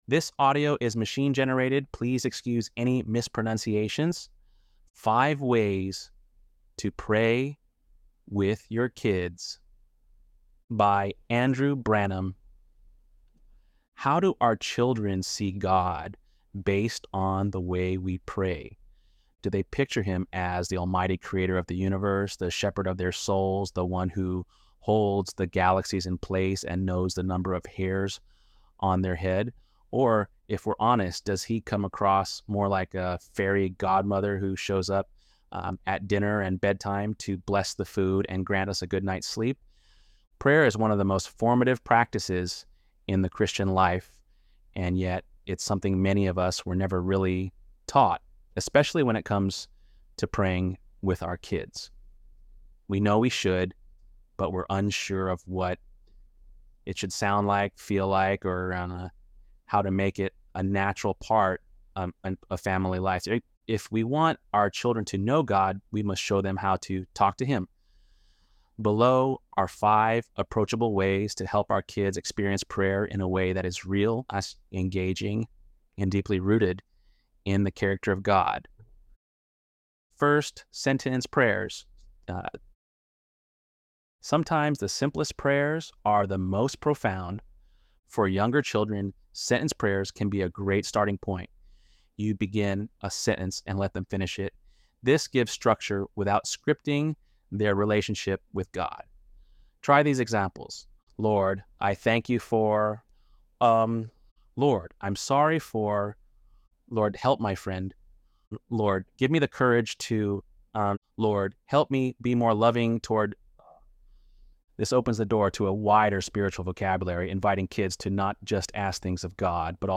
ElevenLabs_6.13_Pray.mp3